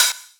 pdh_hat_one_shot_opeen_synthetic_flint.wav